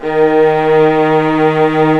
55m-orc05-D#2.wav